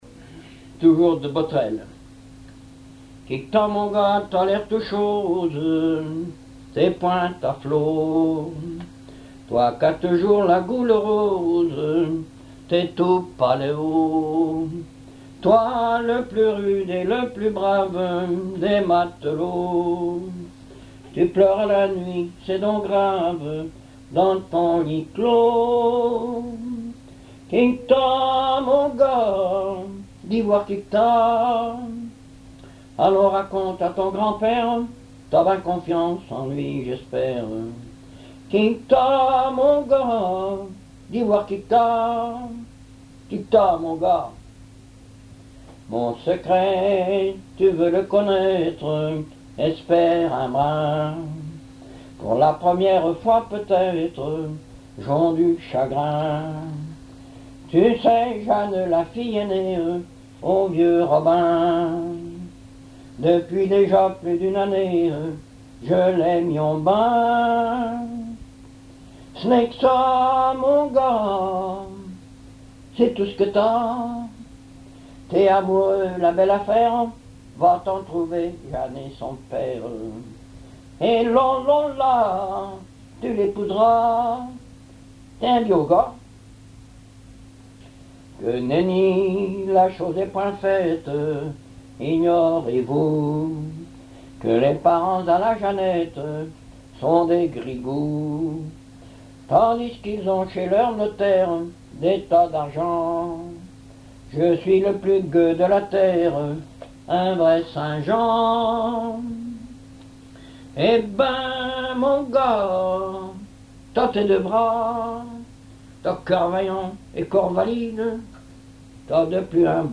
chansons populaires et histoires drôles
Pièce musicale inédite